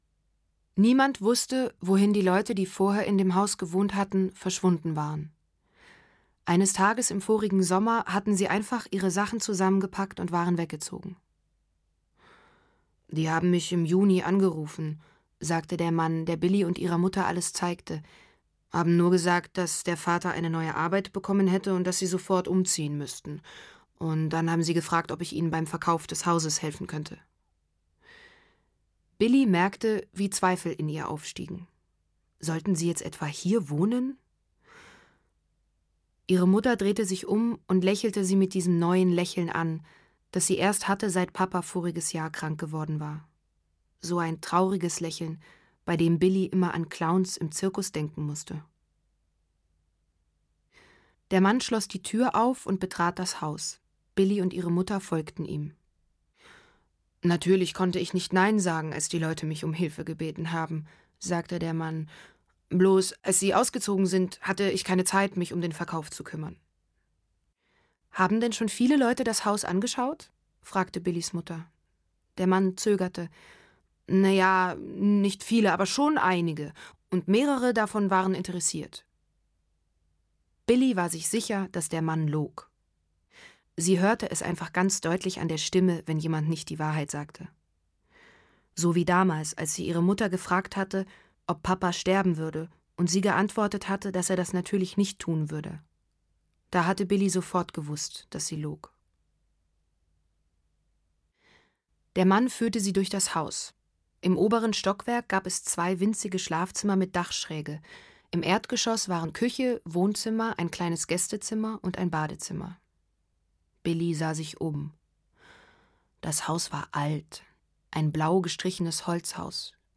Rosalie Thomass (Sprecher)
2014 | gekürzte Lesung